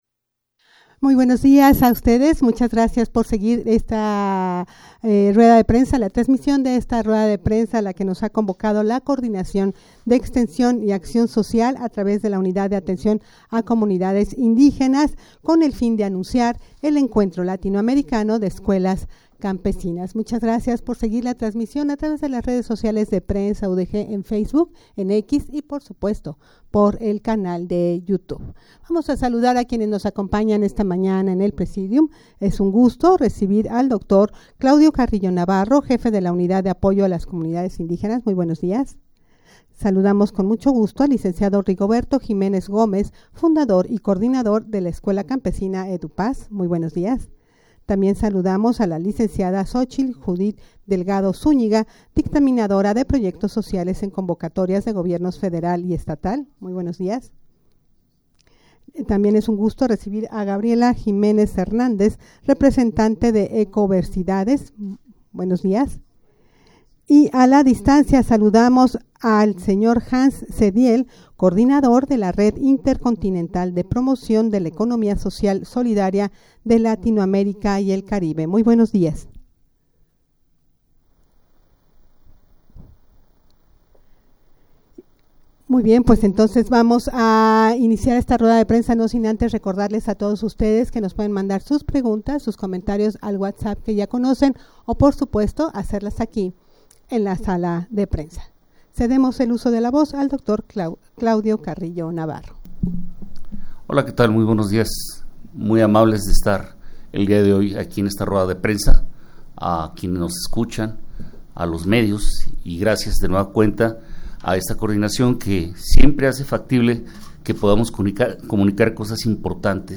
Audio de la Rueda de Prensa
rueda-de-prensa-para-anunciar-el-encuentro-latinoamericano-de-escuelas-campesinas.mp3